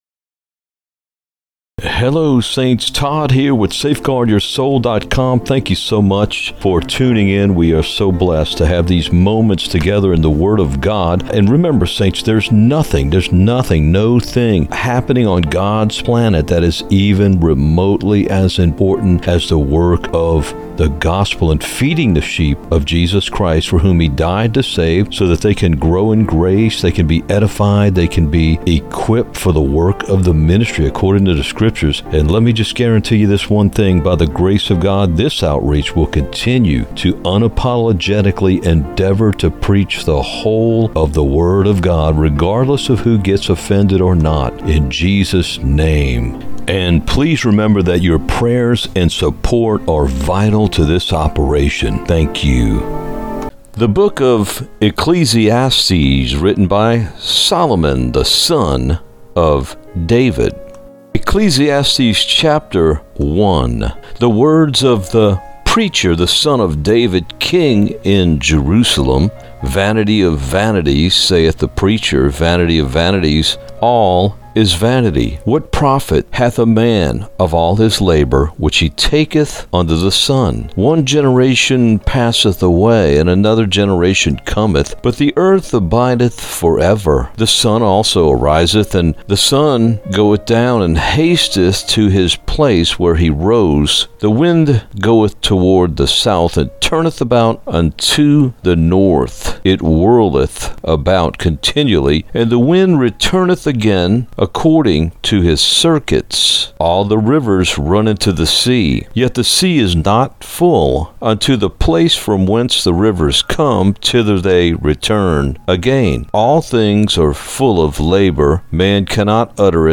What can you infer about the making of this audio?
the-book-of-ecclesiastes-narrated-EDITED-MUSIC.mp3